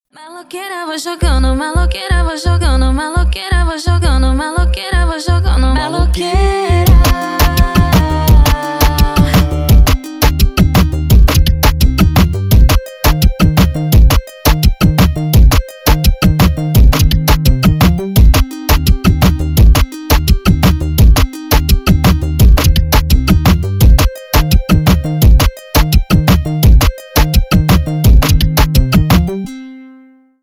Поп Музыка
клубные # латинские